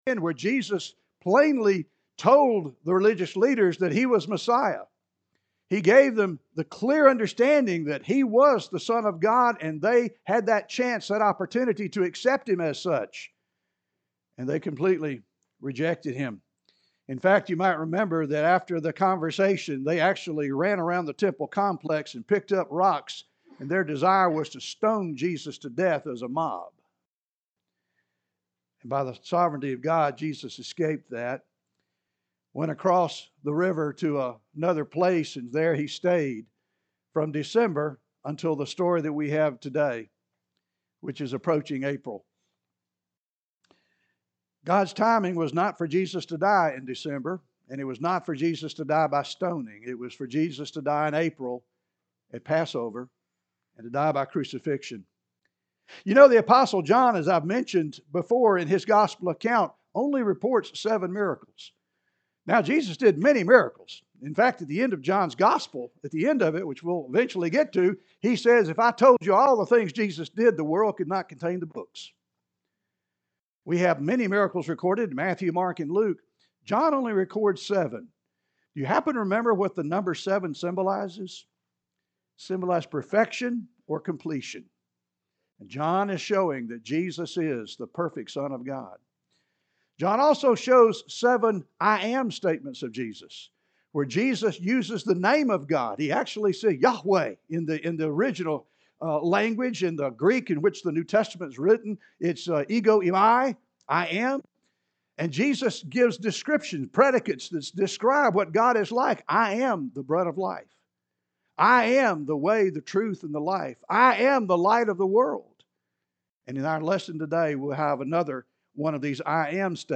(Sermon series)